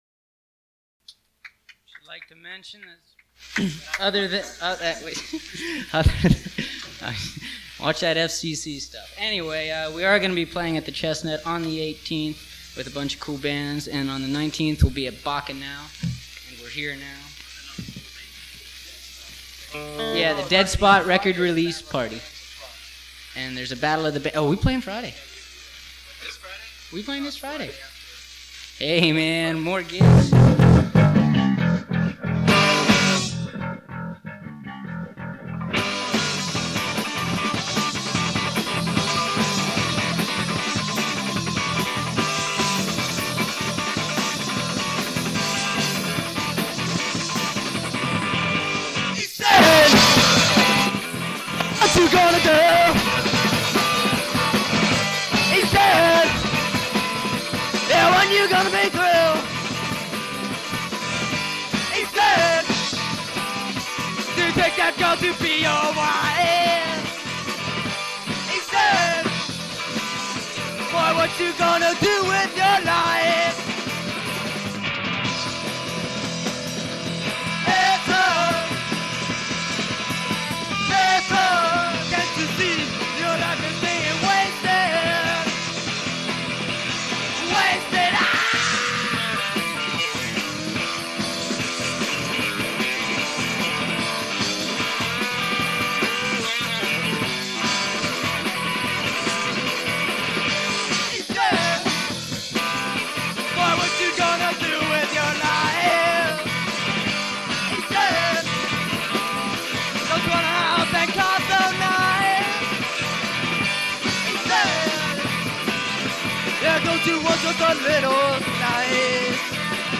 Bass
Drums, Percussion
Guitar
Vocals